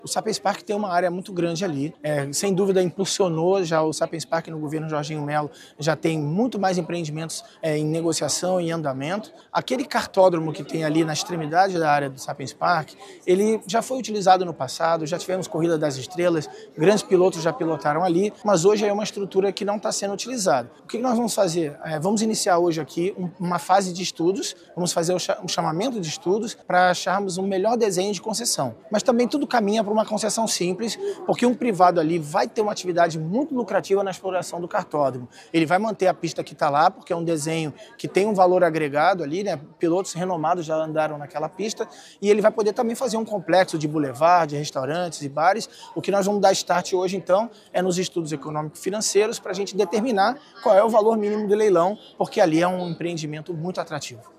A intenção é buscar o desenvolvimento do equipamento para realização de competições esportivas, nacionais e internacionais, que podem fomentar a economia e o turismo da Ilha de Santa Catarina, como explica o presidente da InvestSC, Renato Lacerda: